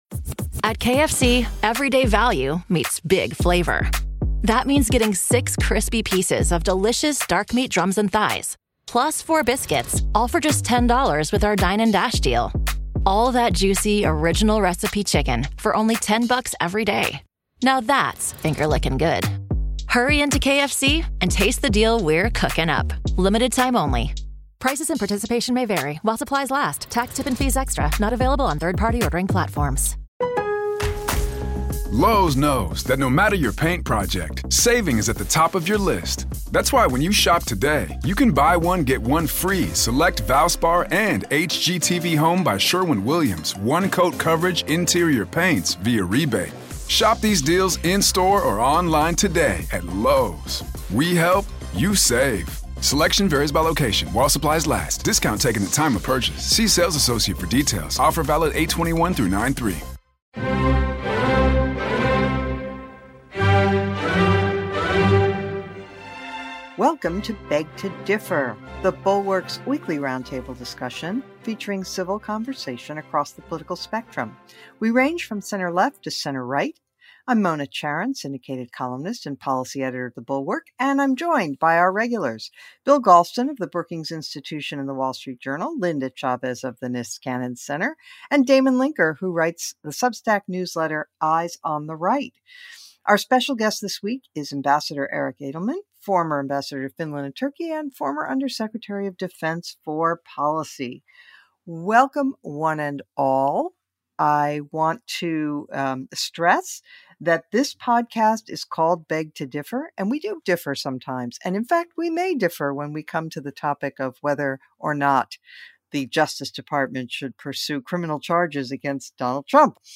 Eric Edelman joins the panel to discuss how to help Ukraine win. The panel also addresses Biden's student loan forgiveness and yea or nay on prosecuting Trump.